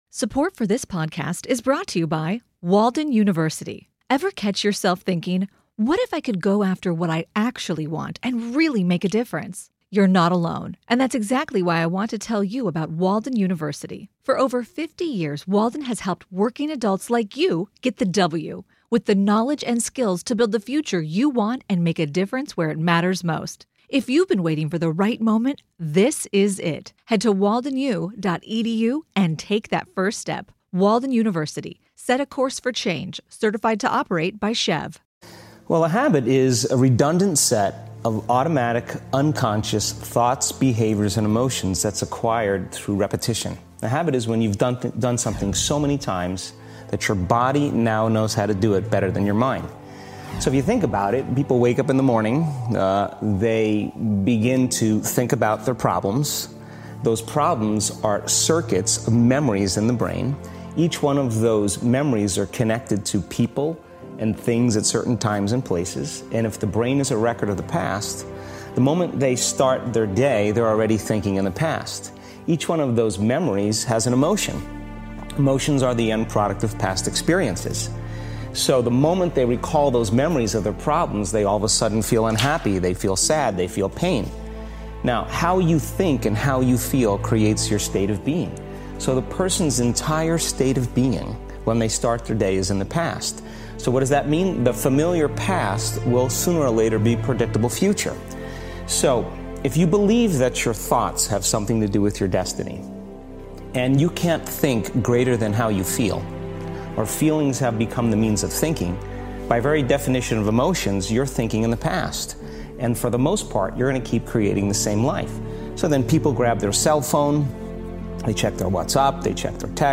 Original Interview by the one and only Tom Bilyeu